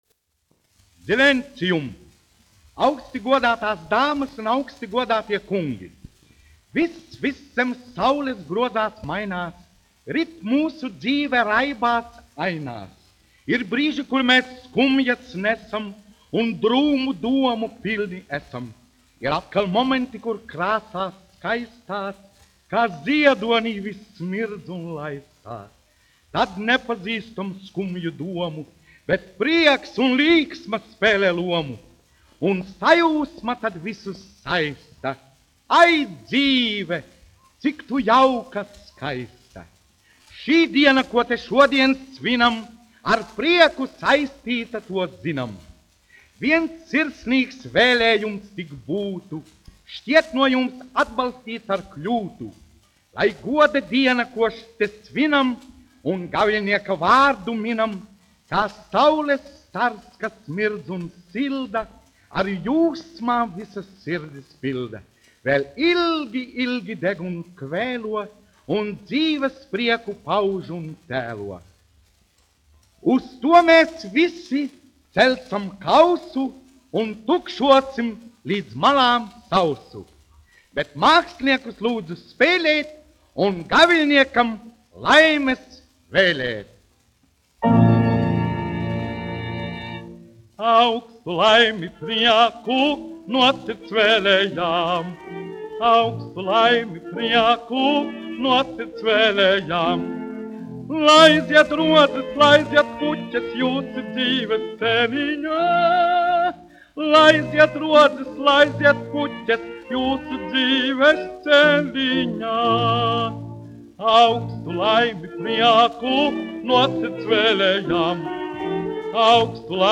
1 skpl. : analogs, 78 apgr/min, mono ; 25 cm
Monologi ar mūziku
Skaņuplate